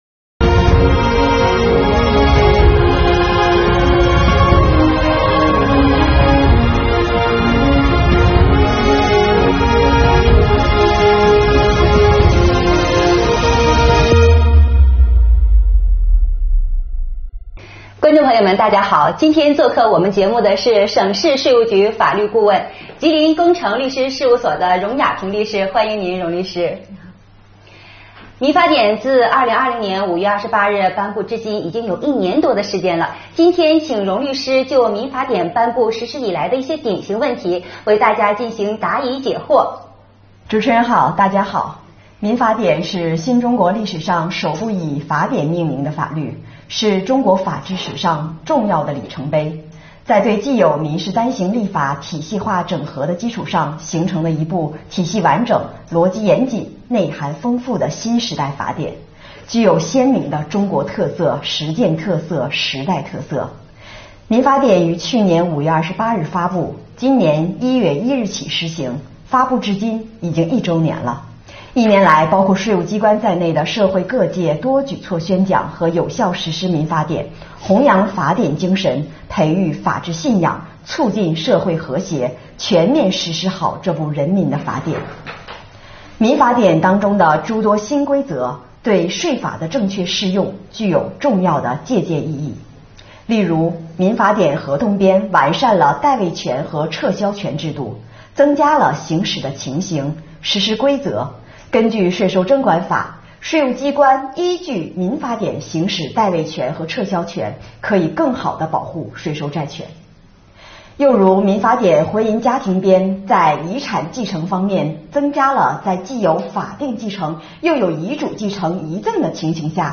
2021年第2期直播回放：《民法典》热点问题解读系列之一